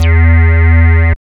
74.06 BASS.wav